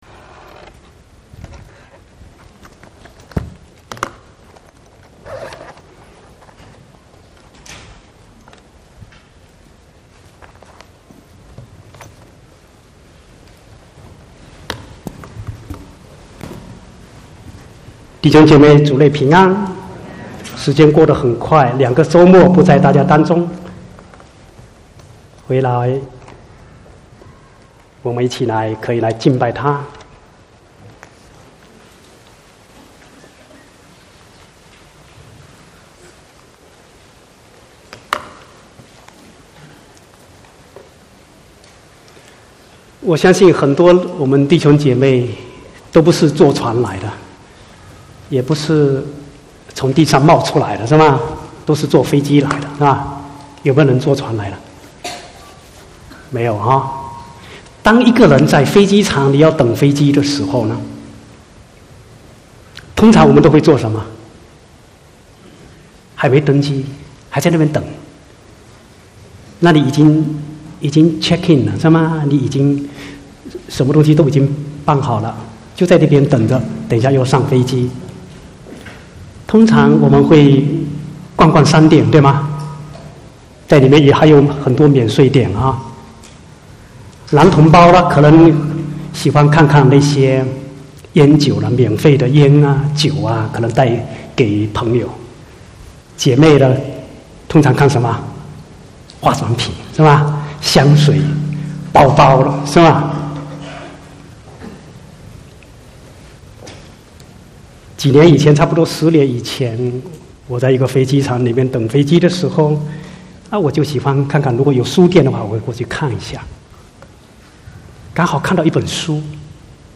26/11/2017 國語堂講道